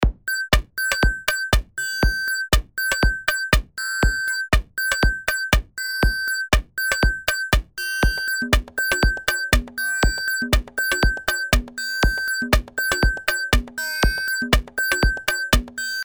Die selbe Hi-Hat mit Ringmodulation, etwas längerem Decay und dem Modell Octave 2 im Distortion-Modul (das auf die Oszillator-Sektion folgt):